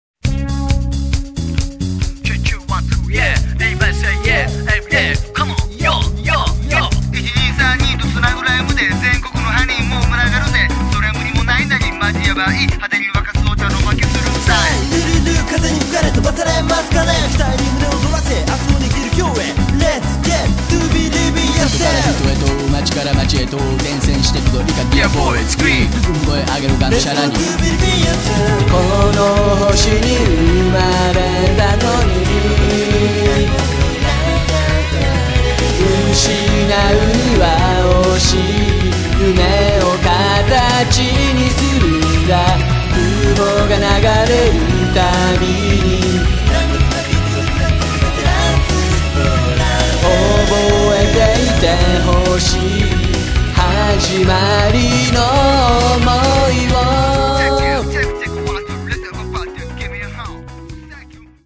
Vocal
Bass